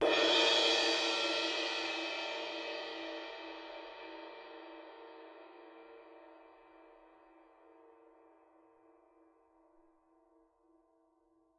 susCymb1-hit_f_rr1.wav